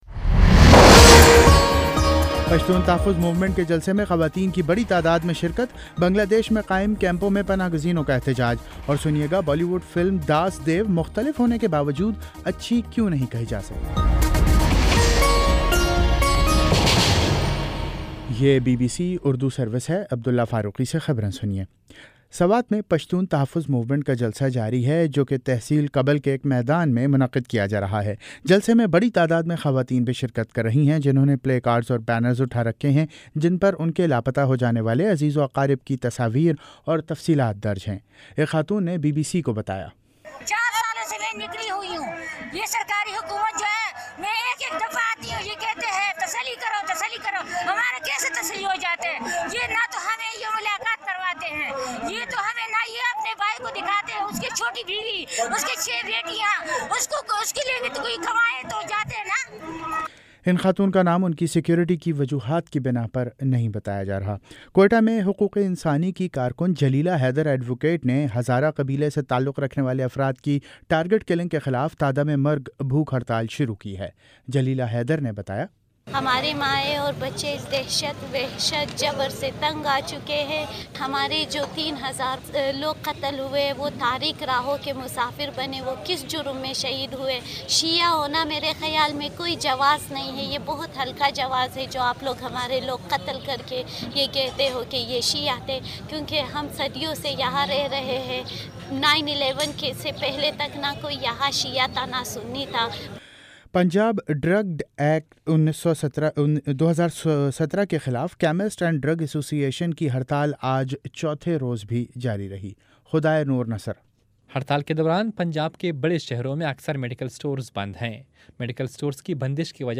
اپریل 29 : شام پانچ بجے کا نیوز بُلیٹن